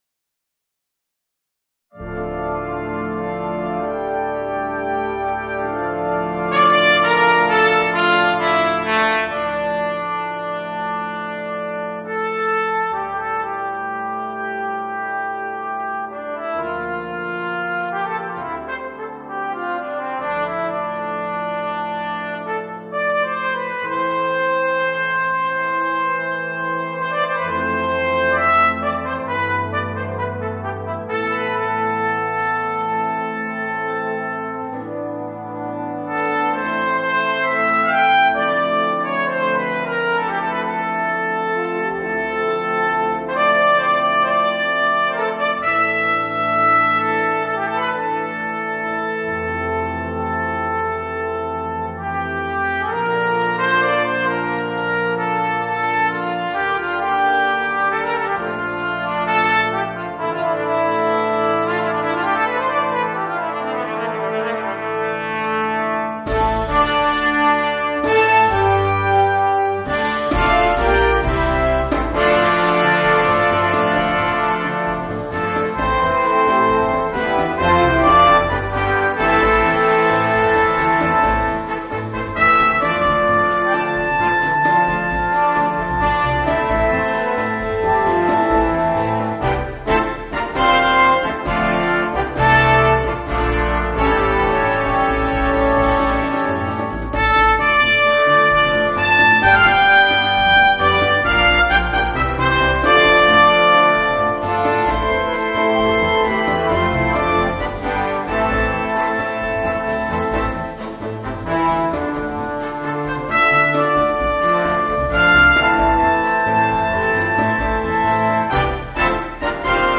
Voicing: Viola and Brass Band